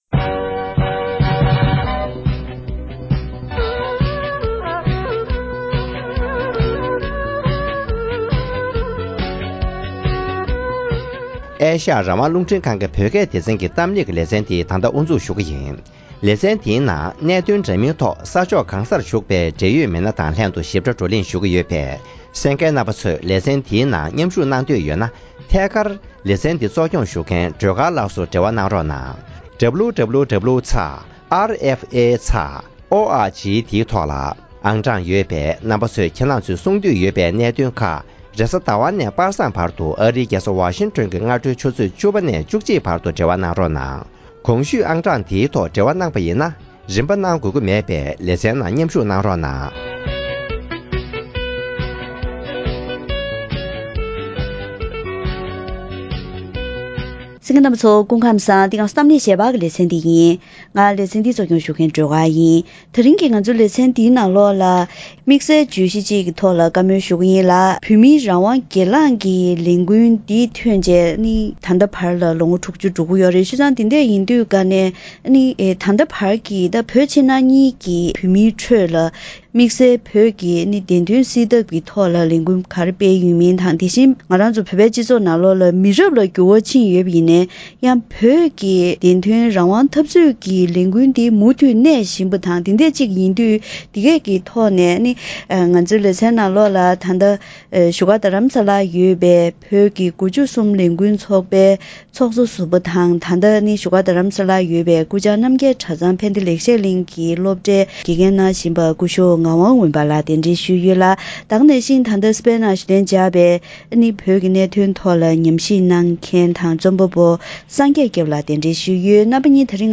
བོད་དོན་མུ་མཐུད་རྒྱལ་སྤྱིའི་ཐོག་གནས་ཐུབ་རྒྱུར་བོད་པའི་མི་རབས་གསར་པས་འབད་བརྩོད་དང་མཇུག་སྐྱོང་བྱེད་བཞིན་ཡོད་པའི་ཐད་བགྲོ་གླེང༌གནང་བ།